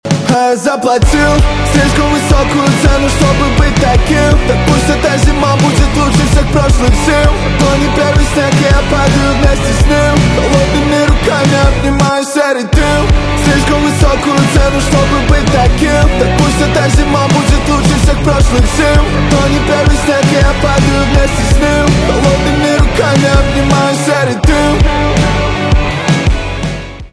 русский рэп , грустные , гитара , барабаны